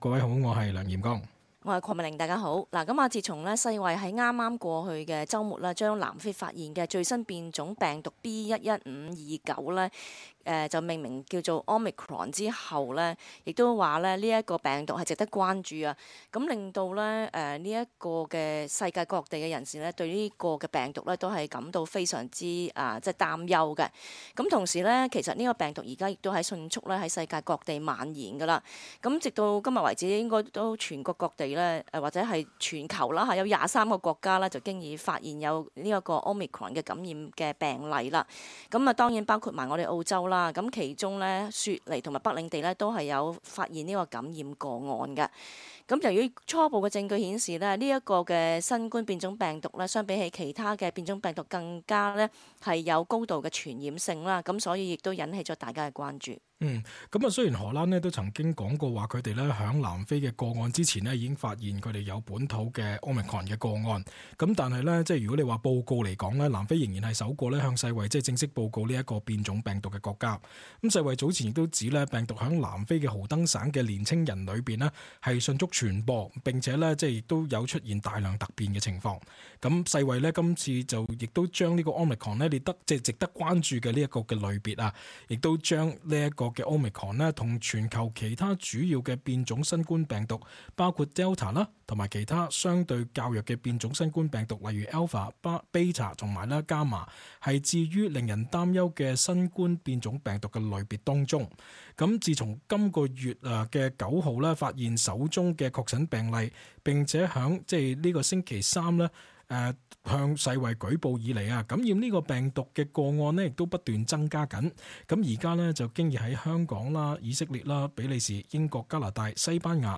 cantonese_-_dec_3_-_talkback_-_upload.mp3